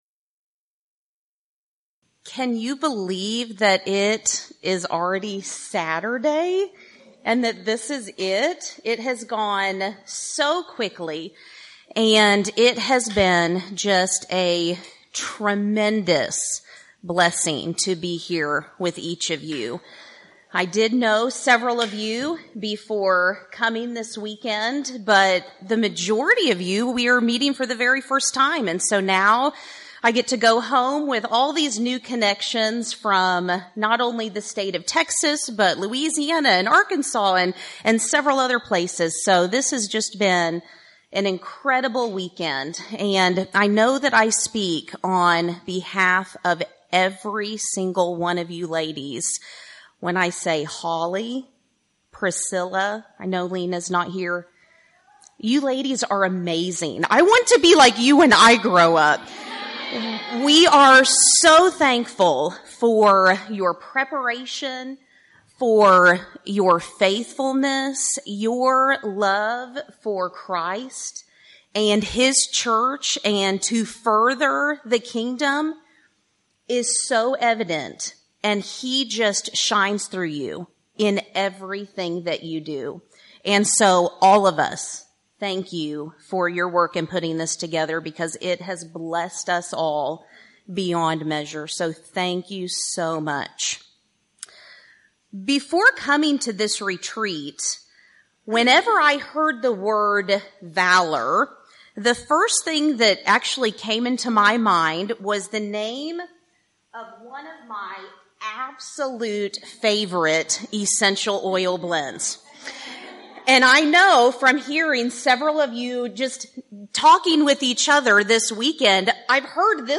Event: 1st Annual Women of Valor Retreat
Ladies Sessions